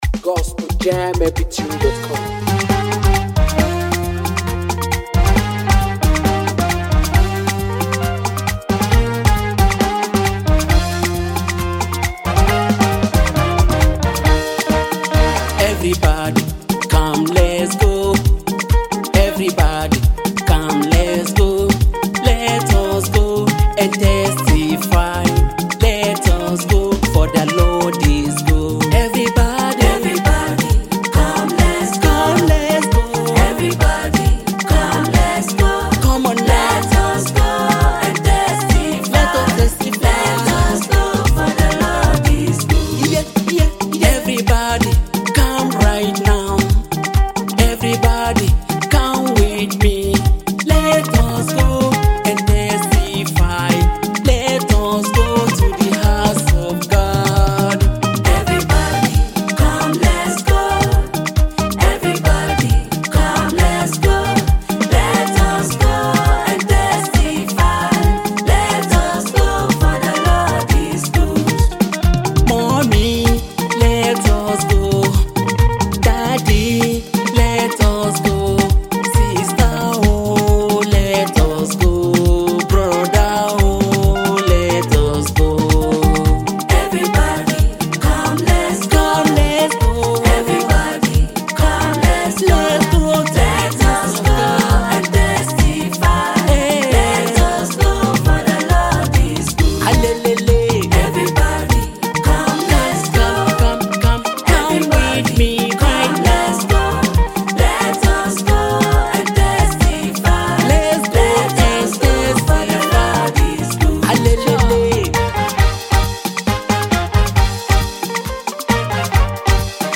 spirit-lifting gospel sound